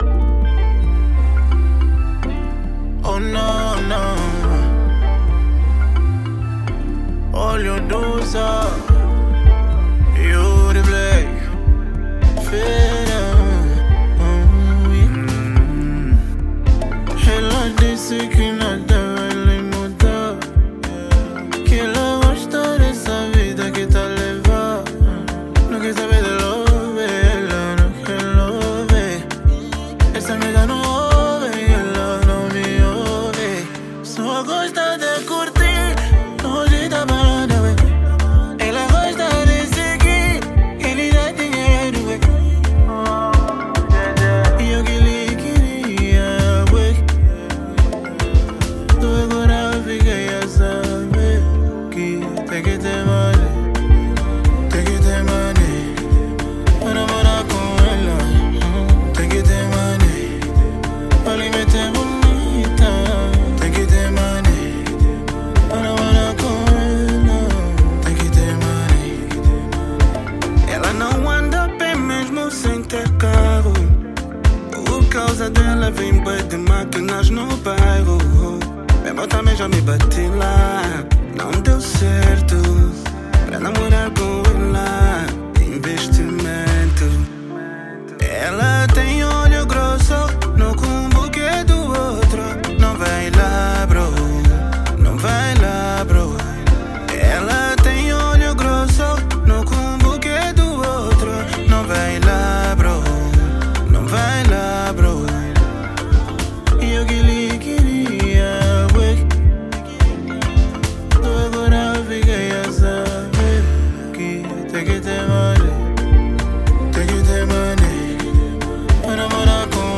Trap
📌 Gênero: Afro Beat